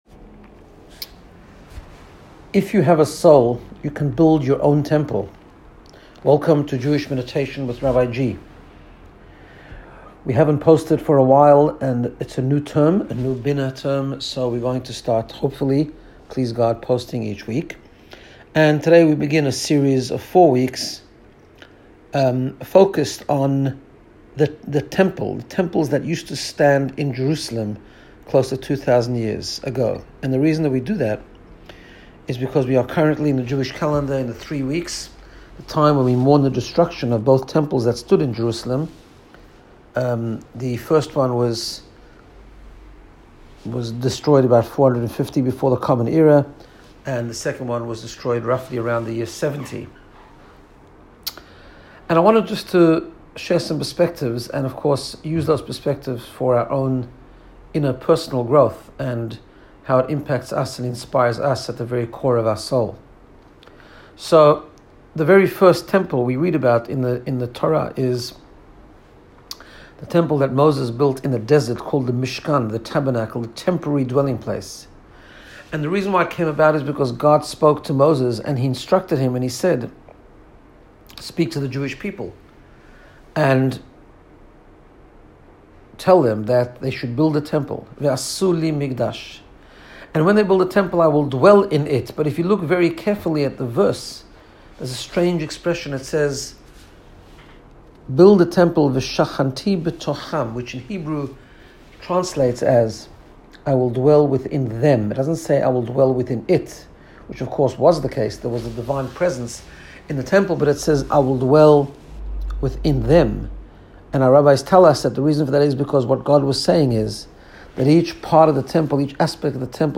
Guided Meditation to get you in the space of Personal Growth and Wellbeing.